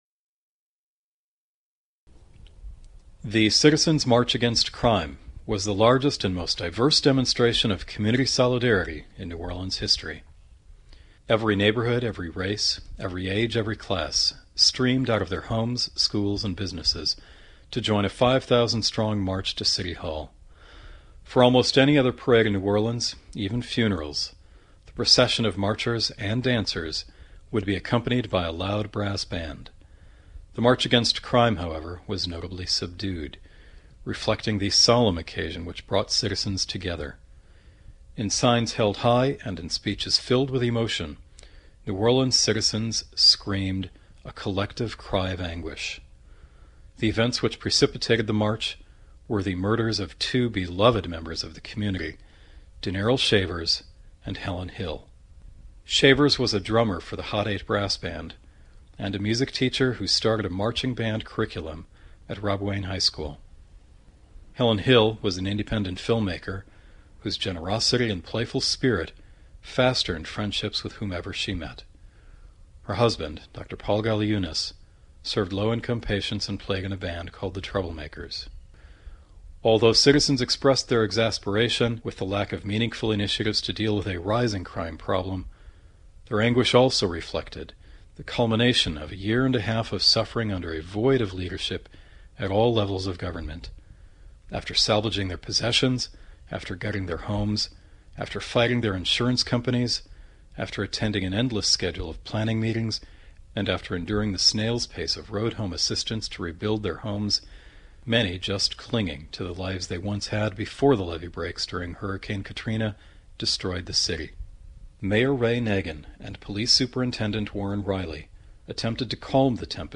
Four coordinated marches united against recent violent crime, consisting of more than a thousand outraged New Orleanian residents and supporters, converged on the City Hall steps around noon today. The crowd overflowed into the lawns, street and park surrounding the building. Several speakers, each from different neighborhoods in the city, spoke candidly about what actions police, politicians, communities and each of us need to take for the city to combat a decades-old issue resulting from New Orleans’ entrenched poverty, lack of quality education and drug culture.